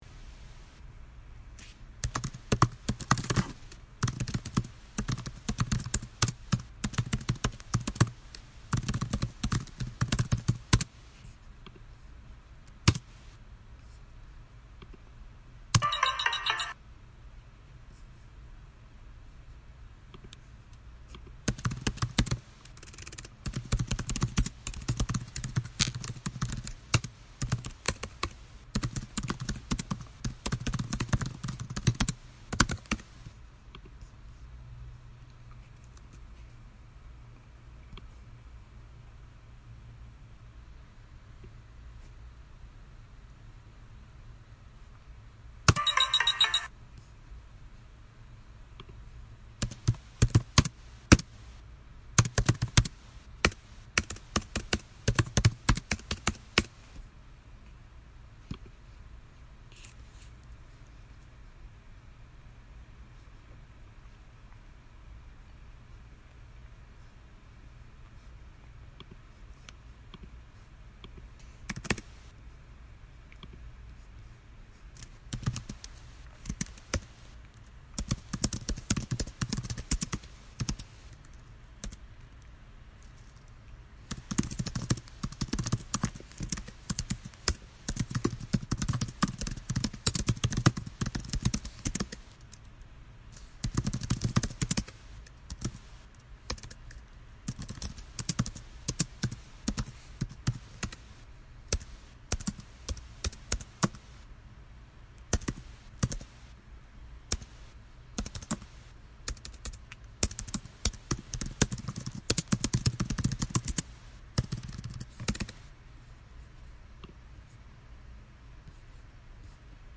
大气磅礴背景音乐
Tag: 大气 磅礴 背景音乐